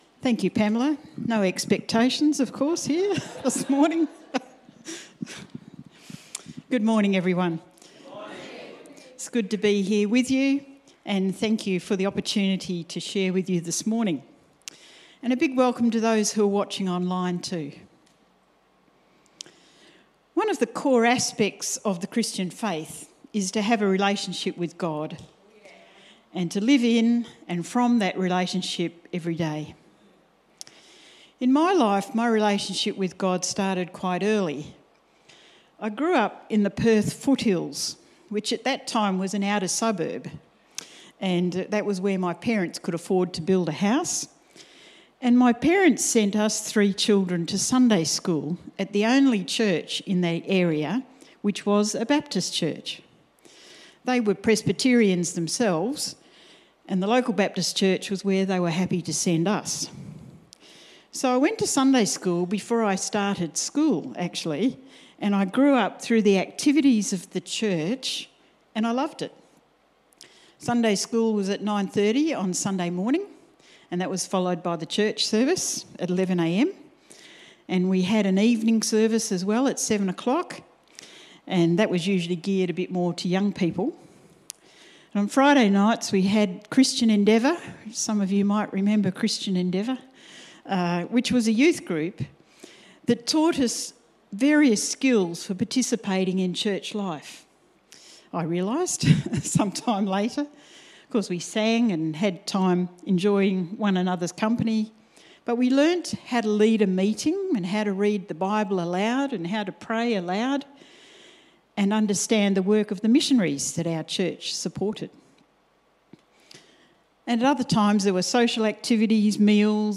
Sermon Transcript Good morning everyone, it is good to be here with you this morning.